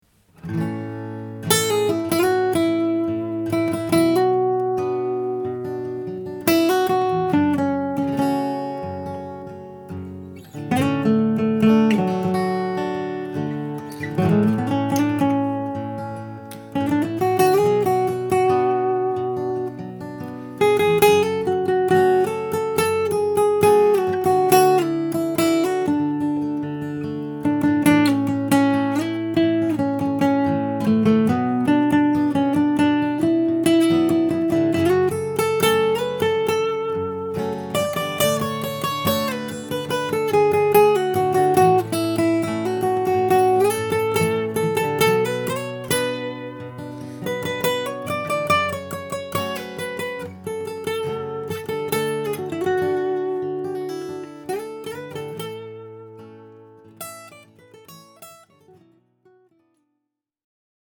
When you get comfortable in the key of A, try another key!